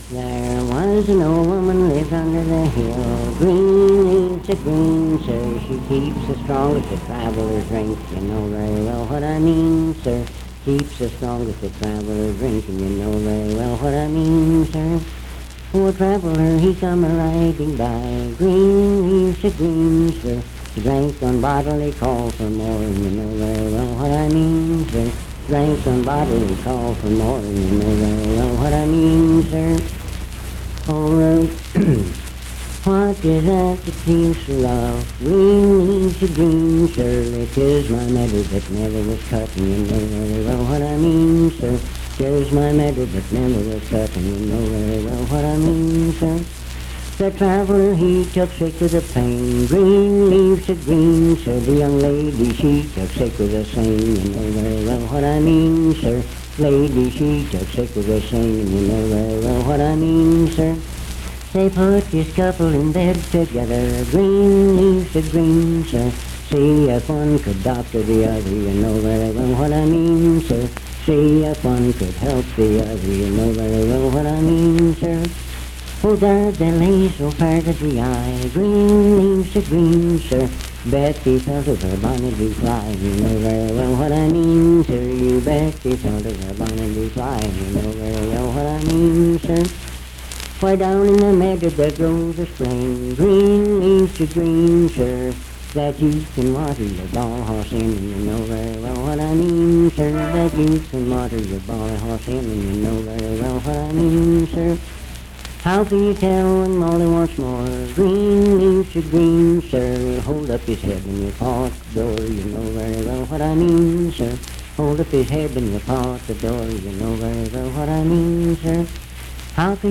Unaccompanied vocal music
Verse-refrain 10(6w/R). Performed in Sandyville, Jackson County, WV.
Miscellaneous--Musical
Voice (sung)